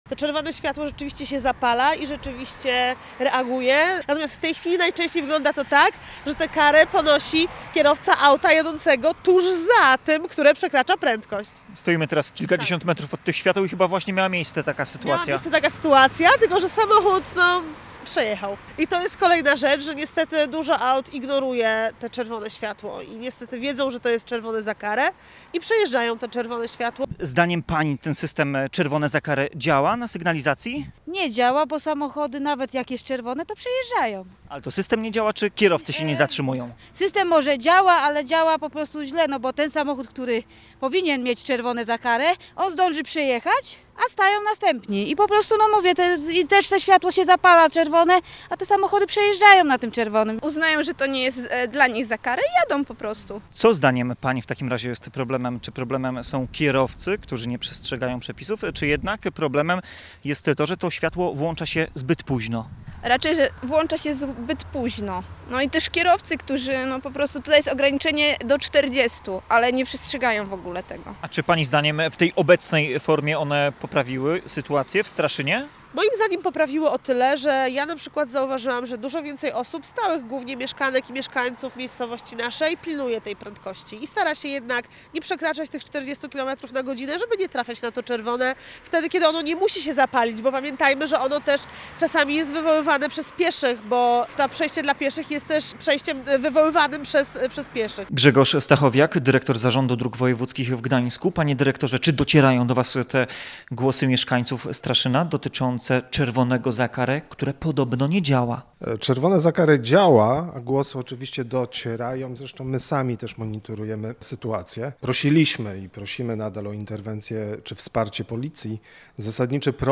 Posłuchaj materiału naszego reportera.